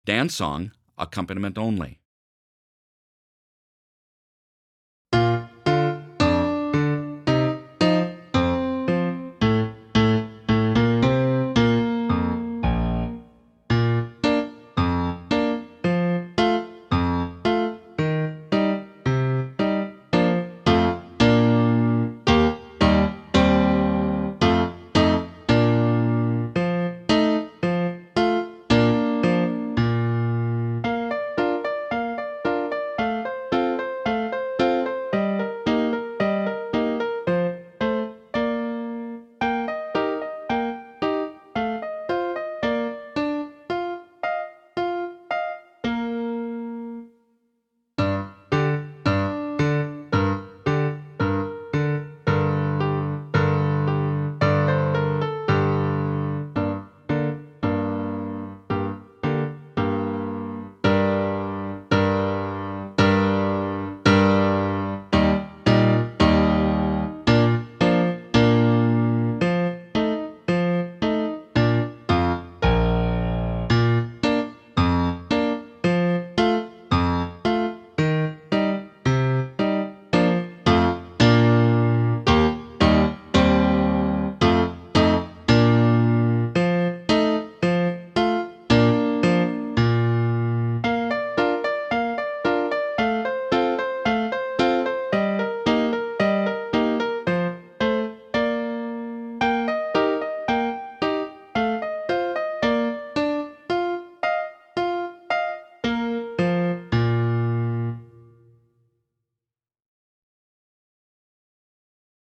Piano Only – Performance Tempo
18-Dance-Song-Accompaniment-Only-1.mp3